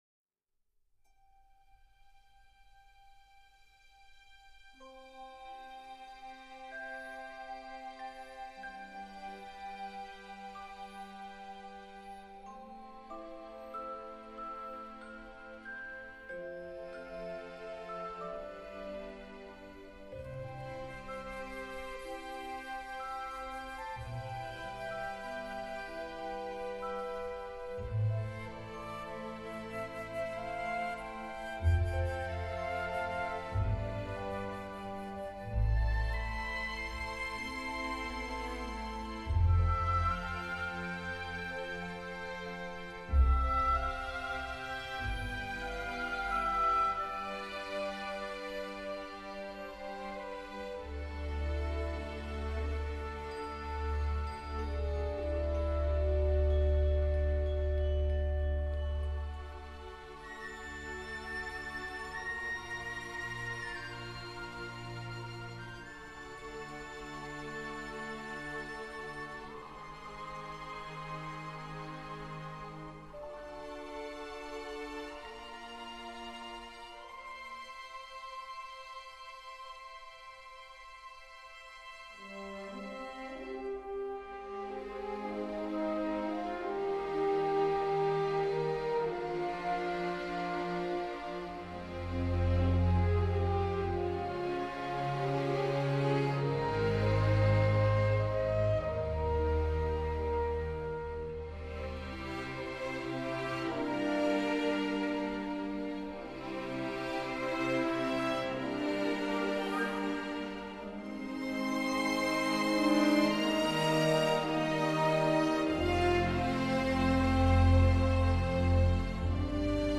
语种：        纯音乐
专辑类别：原声带、影视音乐
资源格式：立体声WAV分轨